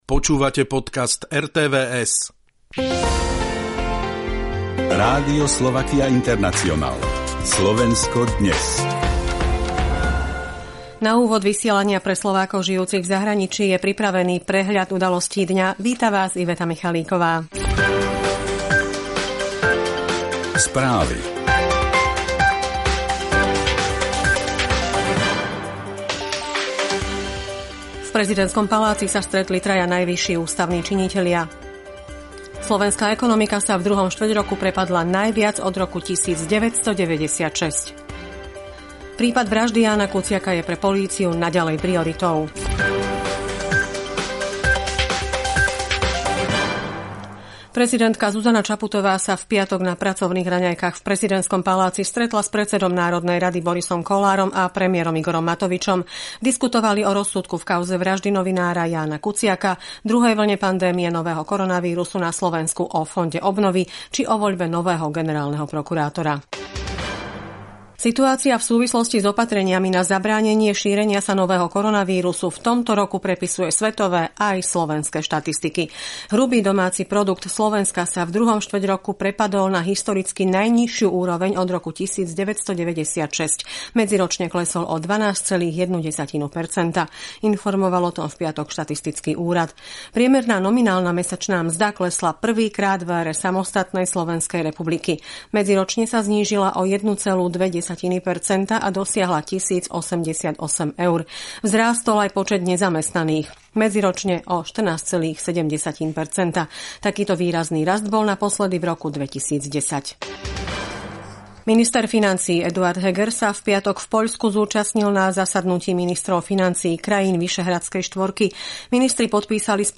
Správy.